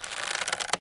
ropes_creak.1.ogg